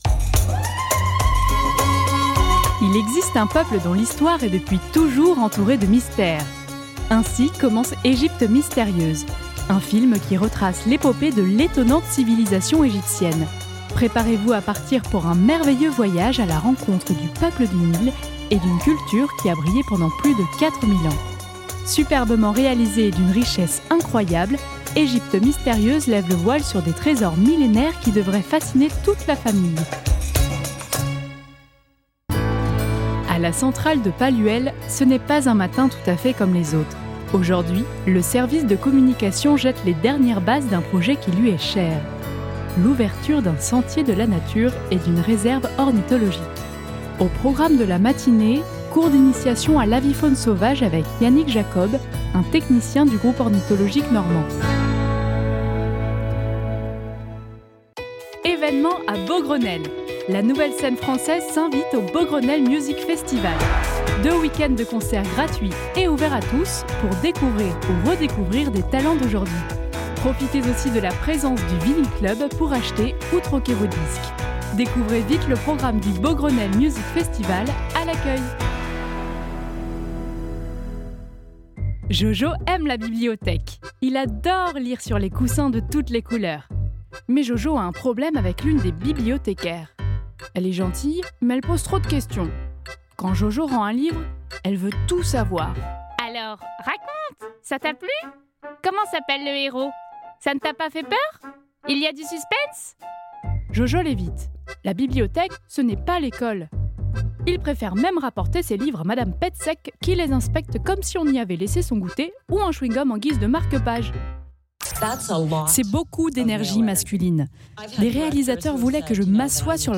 Bande démo voix off
16 - 38 ans - Mezzo-soprano